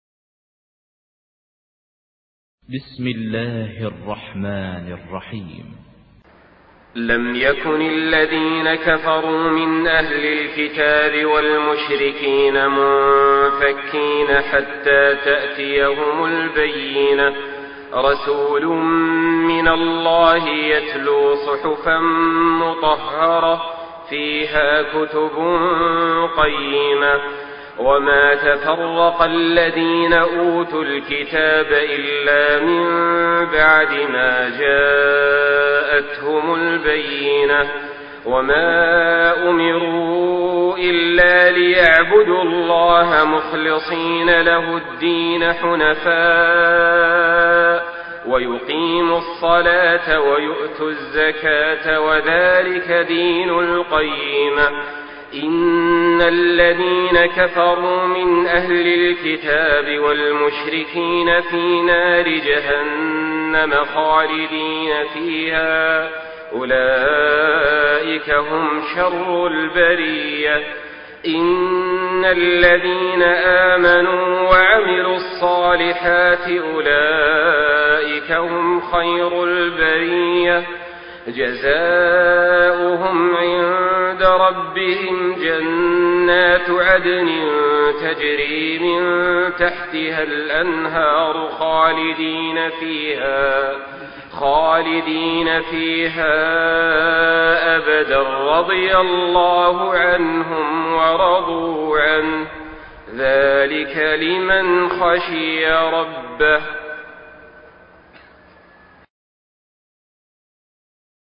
Une récitation touchante et belle des versets coraniques par la narration Hafs An Asim.
Murattal